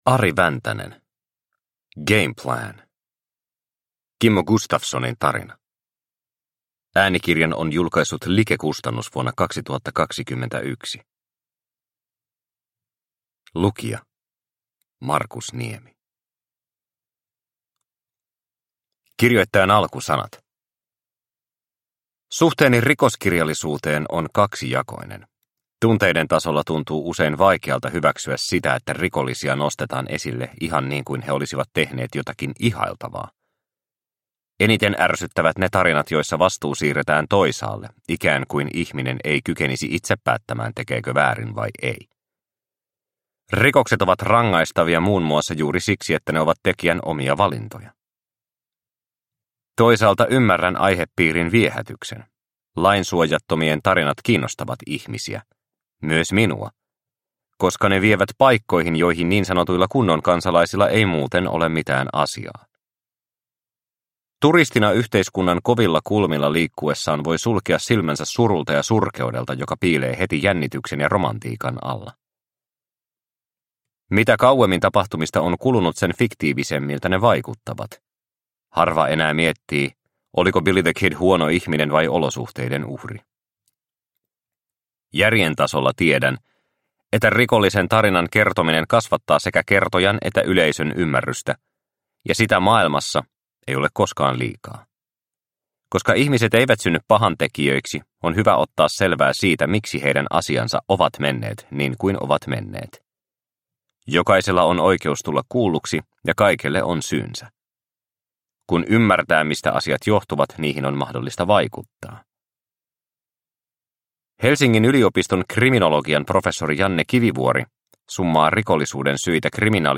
Game Plan – Ljudbok – Laddas ner